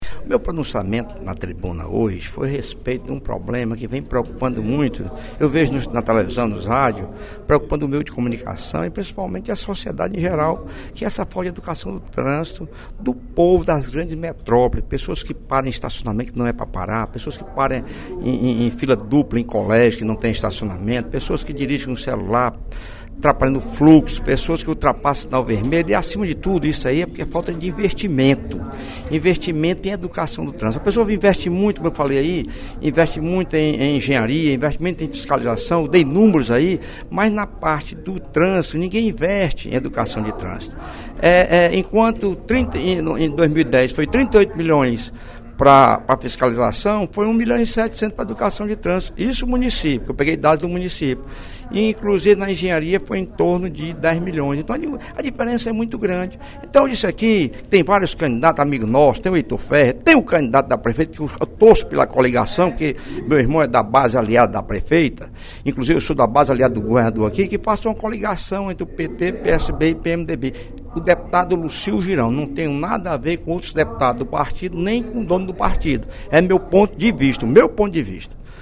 Na sessão plenária da Assembleia Legislativa desta quinta-feira (17/05), o deputado Lucílvio Girão (PMDB) criticou a falta de investimentos em educação para o trânsito.